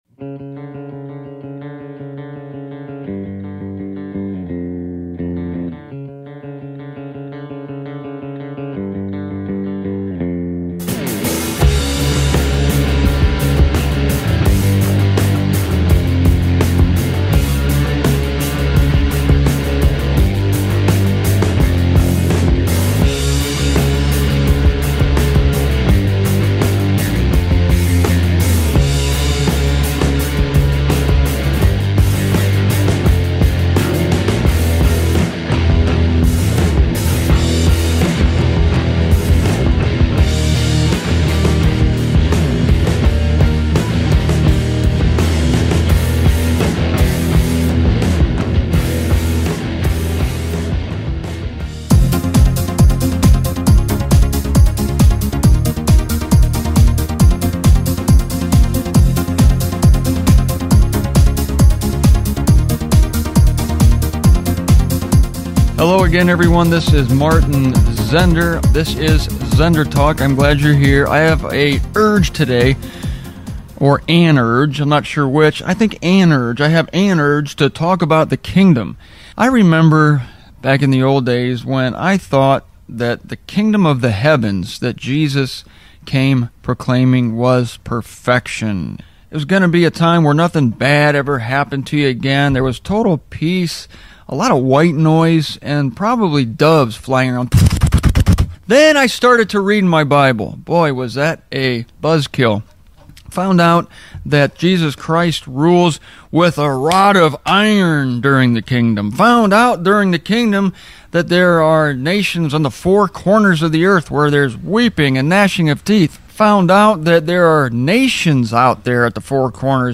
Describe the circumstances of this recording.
Back to ZenderTalk studios in Greenwich, Ohio, in the spring of 2001 for some basic teaching on the millennial kingdom heralded by Christ.